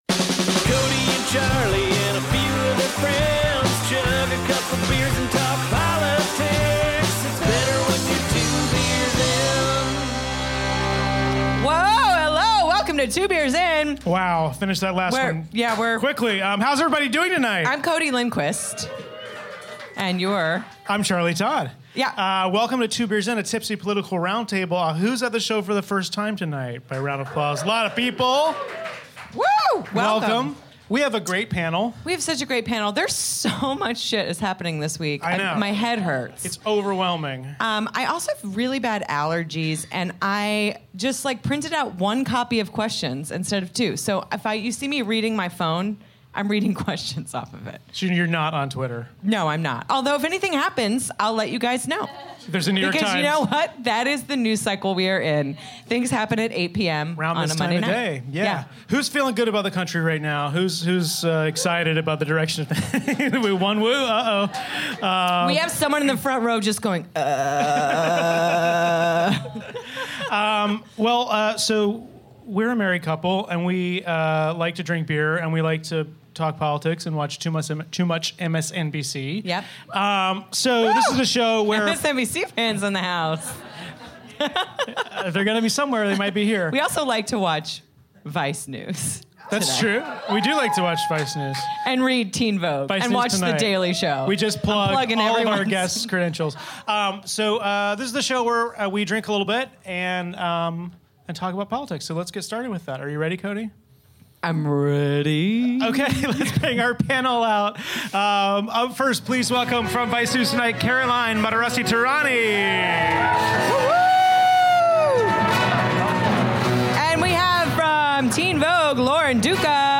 join us for our live show at UCB Theatre East